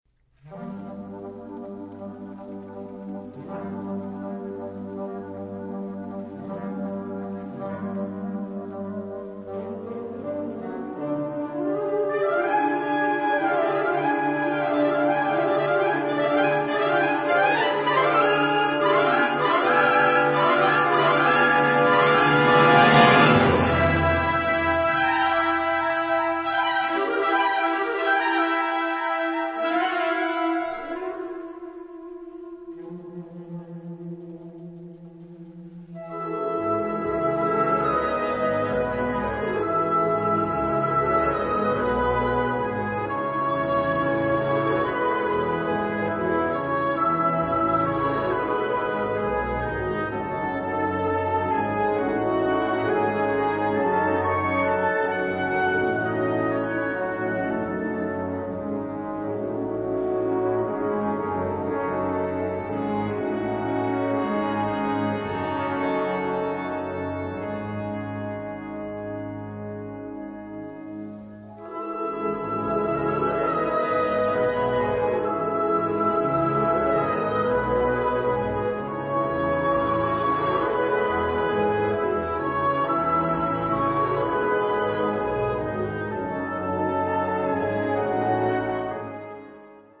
Trp 1,2,3
Perc 1,2,3
pour orchestre à vent symphonique